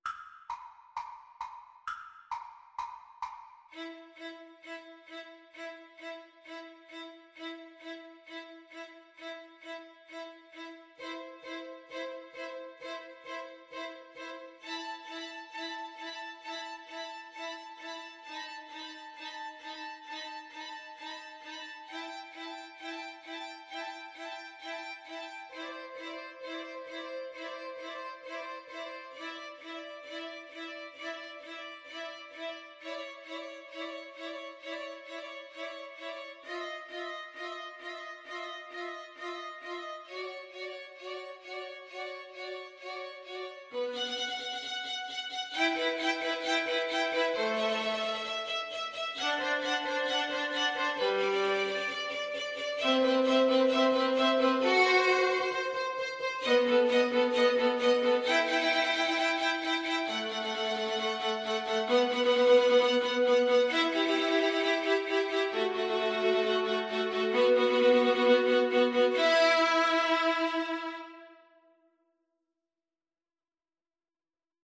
Allegro non molto =c.132 (View more music marked Allegro)
Violin Trio  (View more Easy Violin Trio Music)
Classical (View more Classical Violin Trio Music)